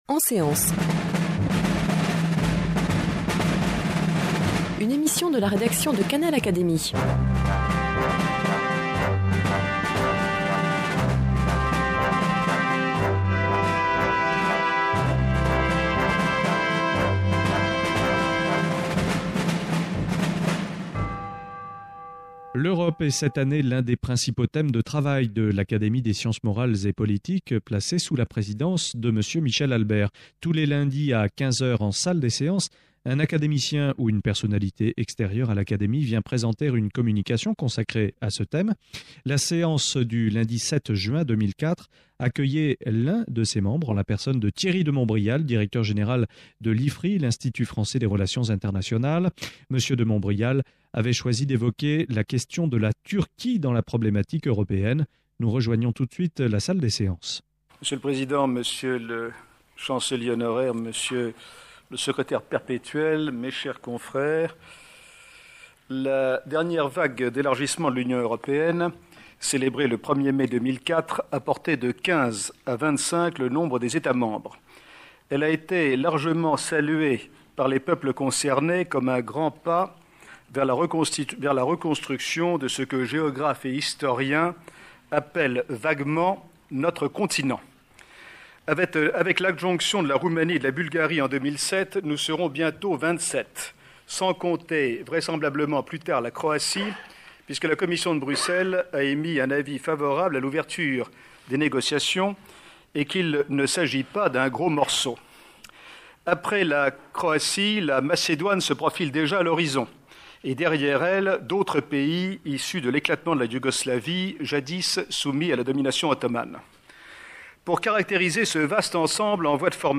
Thierry de Montrial, dans sa communication à l'Académie le 7 juin 2004, ouvre des pistes de réflexion qui sont de trois ordres : historique, politique, et religieux pour dépasser les peurs qui empoisonne le débat sur «la question turque».